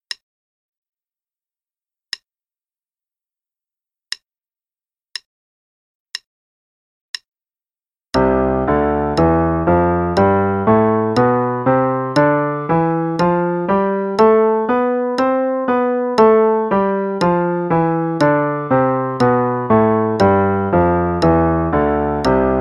Play-along: C Major Scale (8ths, qn=60)
Play-along_C Major Scale (8ths, qn=60).mp3